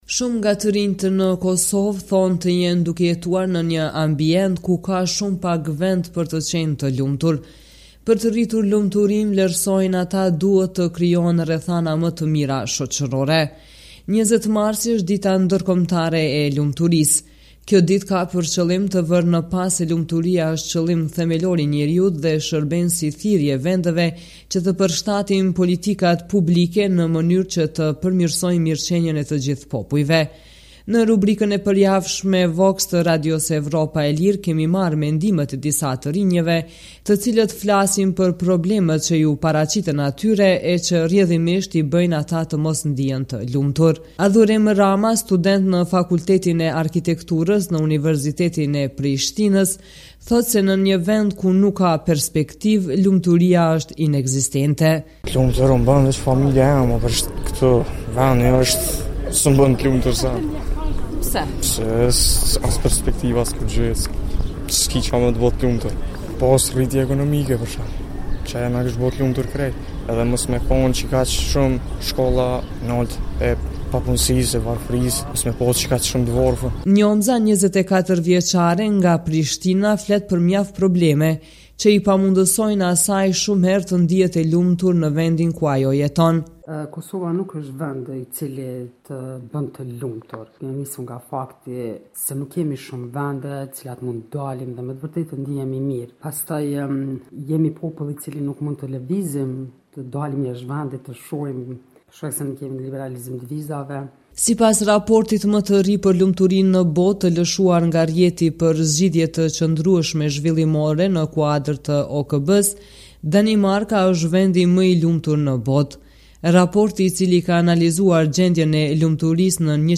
Në Ditën Ndërkombëtare të Lumturisë, Radio Evropa e Lirë sjell mendimet e disa të rinjve të Kosovës të anketuar për rubrikën e përjavshme “VOX”, se sa të lumtur janë ata.